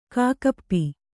♪ kāka ppi